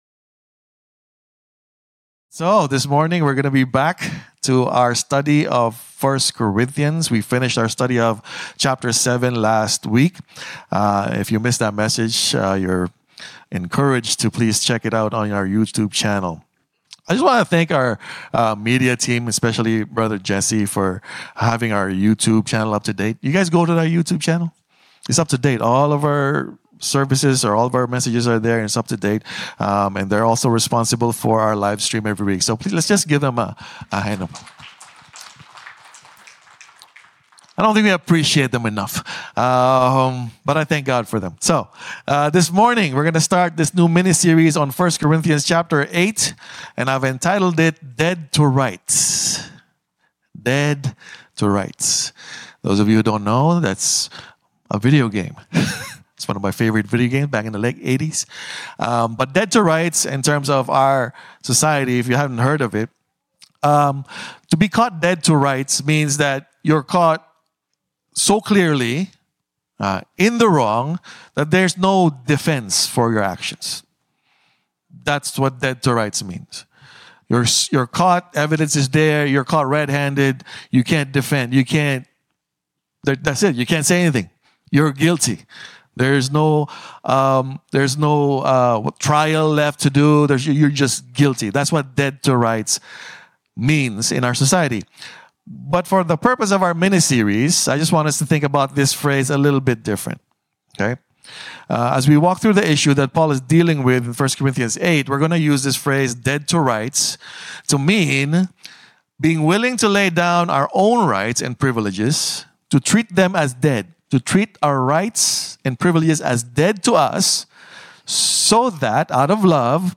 Ultimately, the sermon challenges us to consider whether our actions and freedoms serve to strengthen our community or inadvertently create stumbling blocks for those around us.